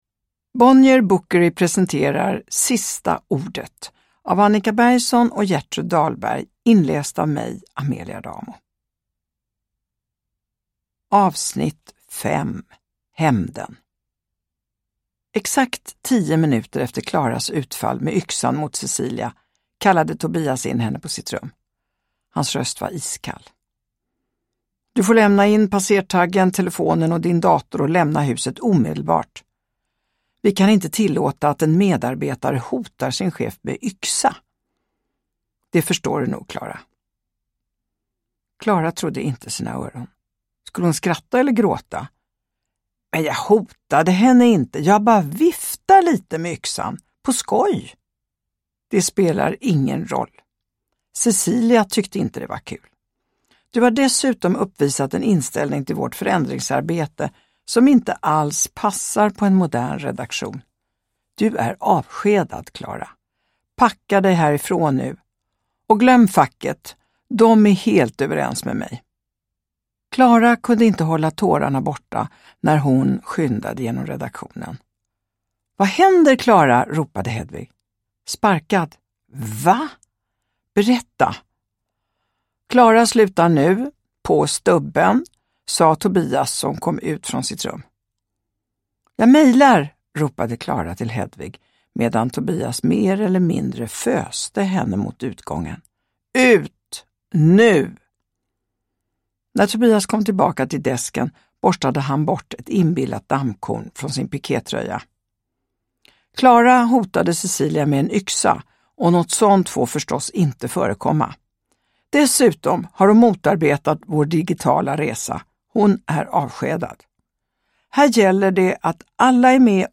Uppläsare: Amelia Adamo